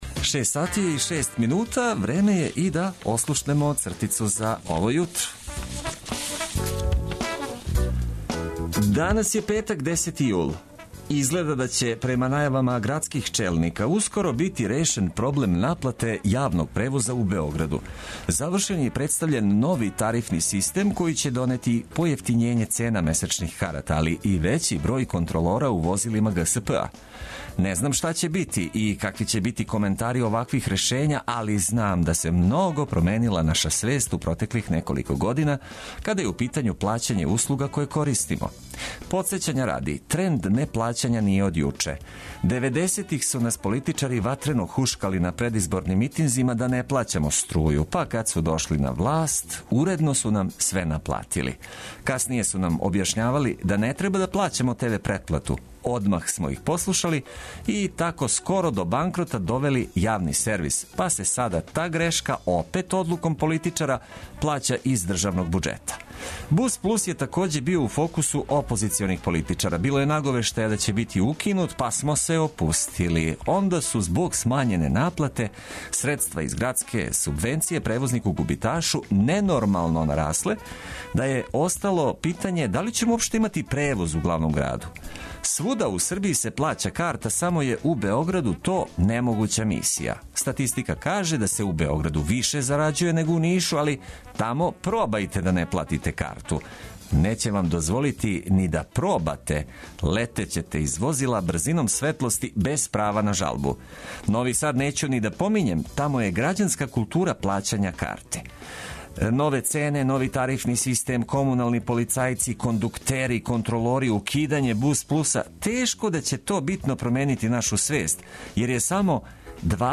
Нека дан почне уз добру музику коју ћемо прошарати информацијама од користи за започињање новог дана.